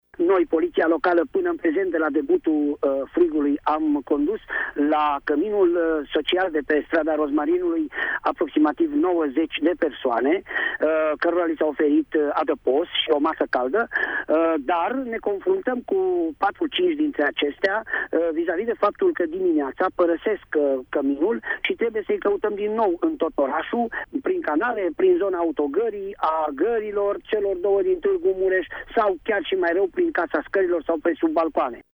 De asemenea, Valentin Bretfelean a precizat că până în acest moment, politiștii locali au condus la Centrul Social de zi de pe strada Rozmarinului în jur de 90 de persoane fără locuință: